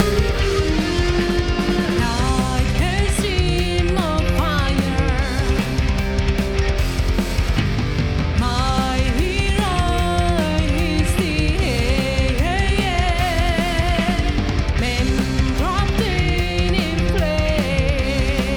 Can´t get the vocals to fit in the mix...(power/epic metal)
Hello everyone, I´m making a home made recording of a power metal-ish song, and it´s my first time mixing vocals.
I´ve already watched a ton of videos and tried all the normal techniques such as reverb, parallel comp, but I feel that it´s still too separated from the instrumental part.